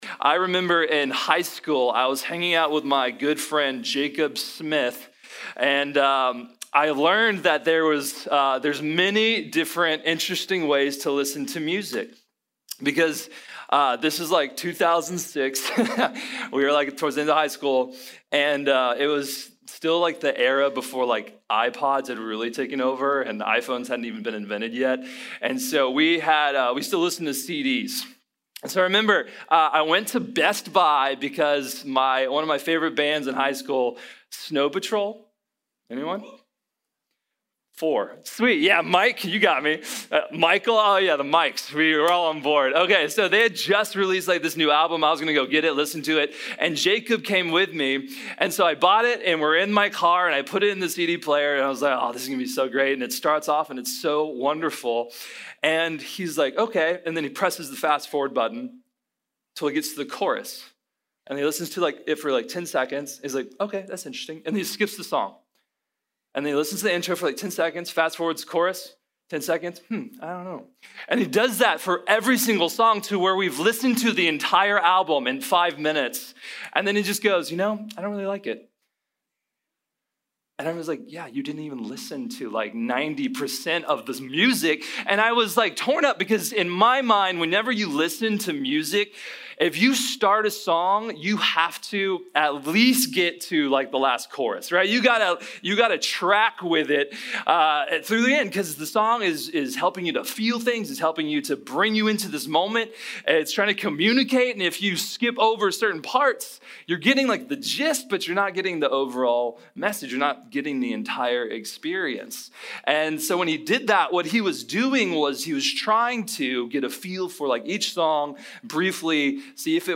The Whole Story | Sermon | Grace Bible Church